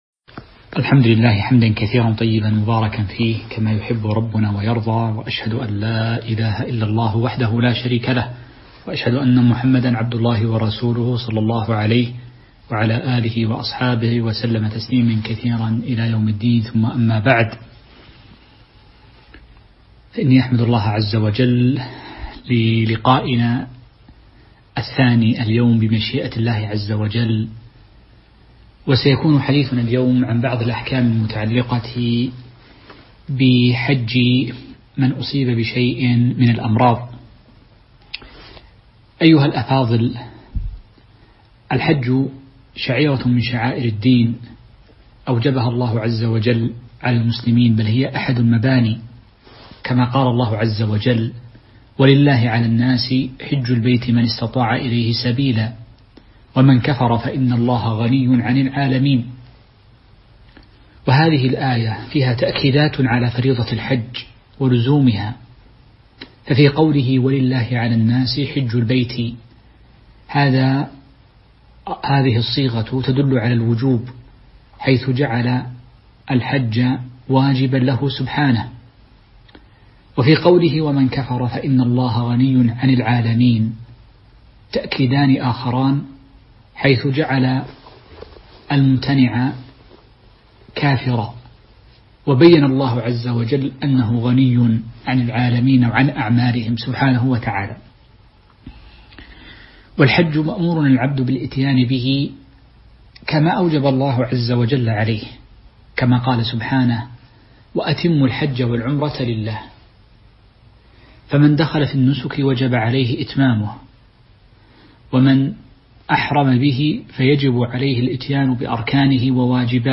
تاريخ النشر ٦ ذو الحجة ١٤٤١ هـ المكان: المسجد النبوي الشيخ